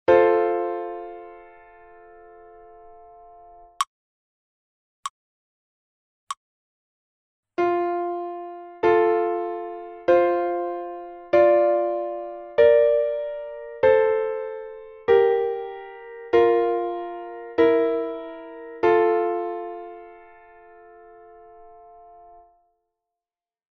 Dvojhlasa_melodie_02_FAC_FAST.mp3